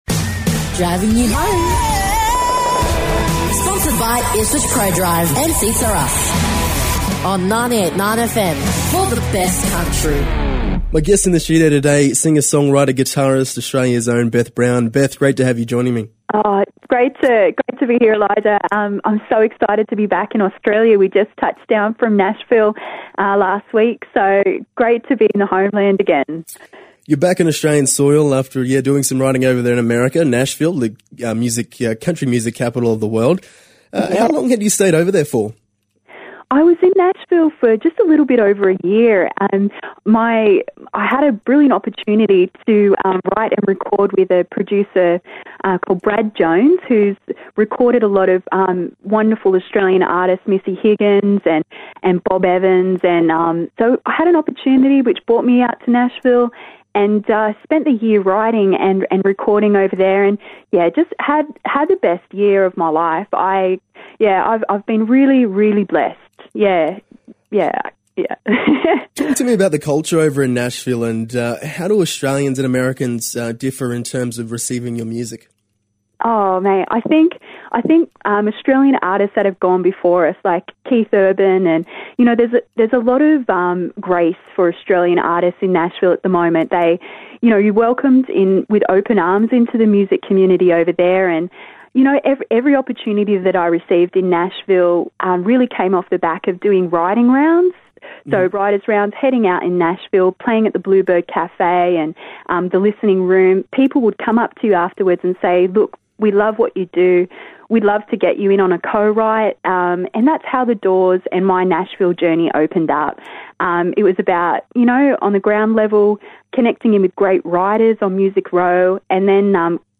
chats